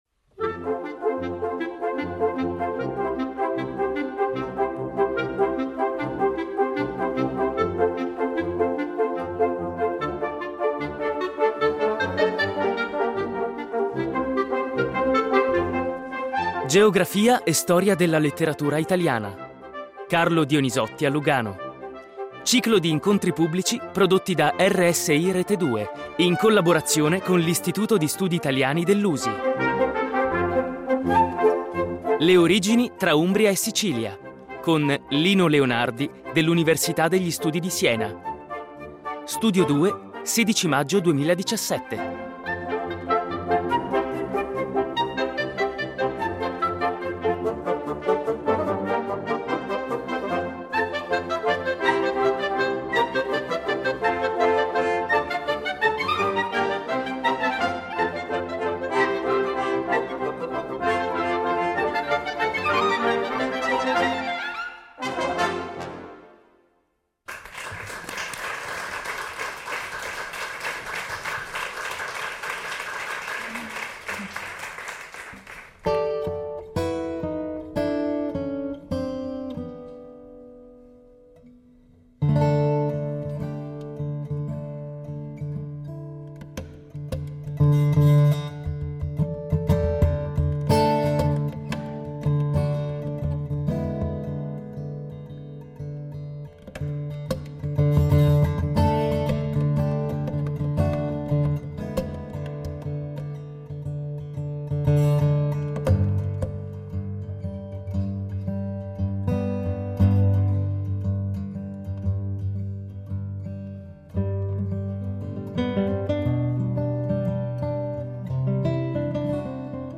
Carlo Dionisotti a Lugano - Ciclo di incontri pubblici prodotti da RSI Rete Due